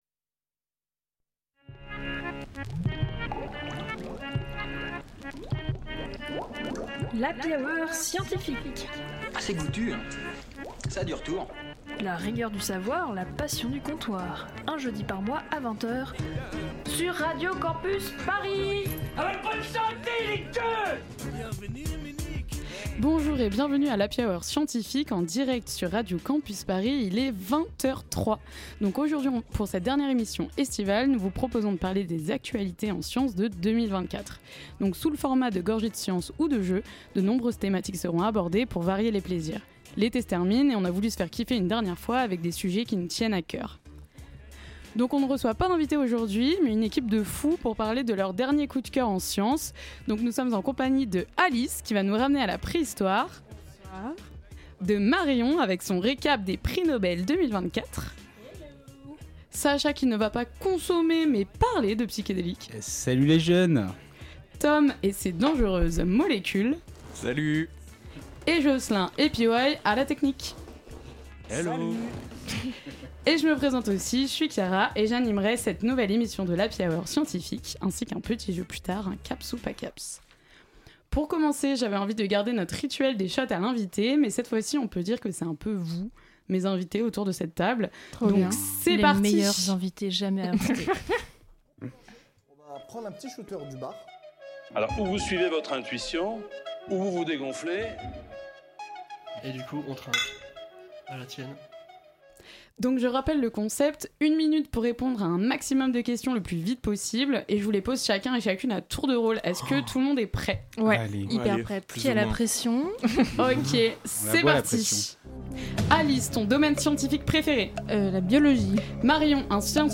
Type Magazine Sciences